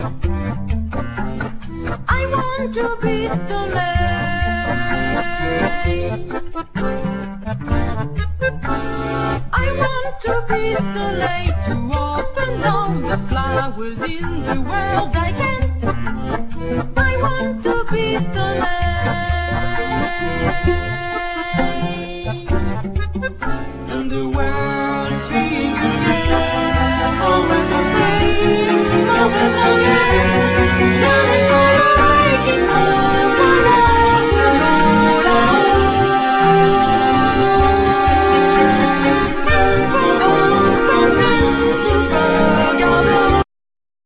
Accordeon,Vocals
Violin,Viola,Vocals
Cello
Drums,Percussions